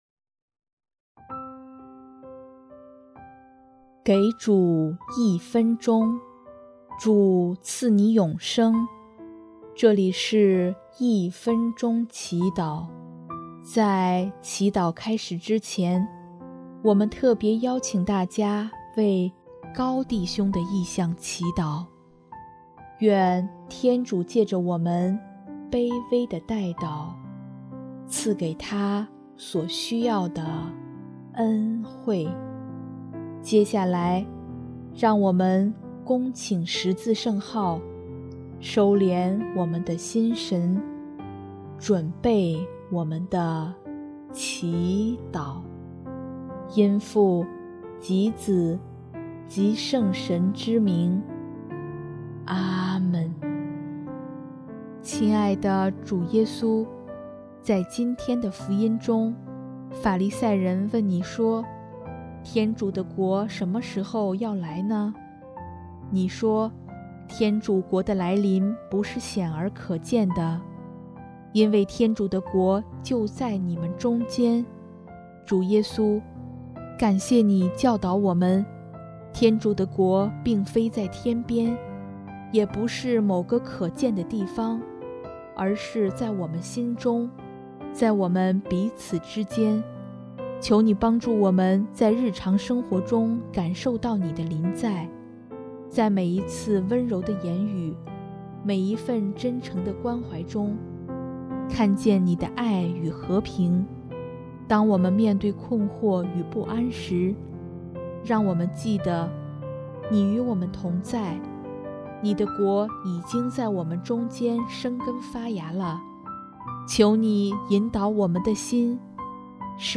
【一分钟祈祷】|11月13日 天主的国就在我们中间